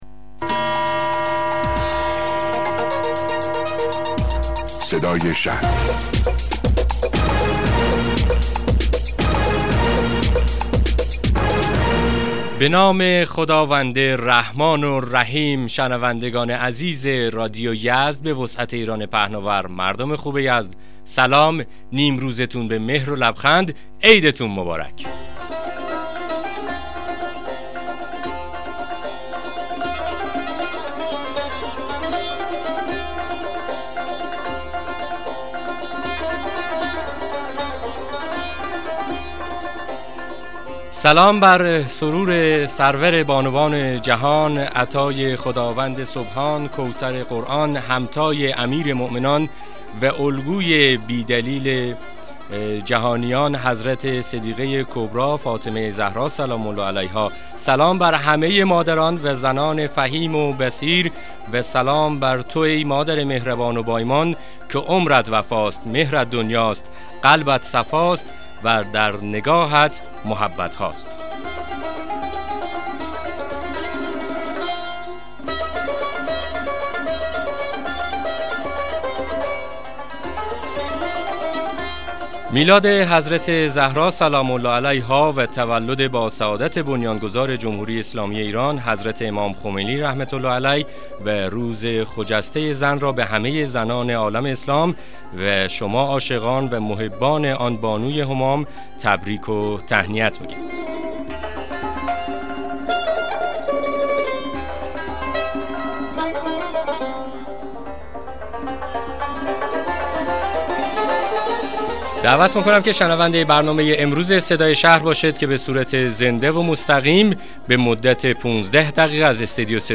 مصاحبه رادیویی برنامه صدای شهر با حضور فاطمه زارع عضو شورای اسلامی شهر یزد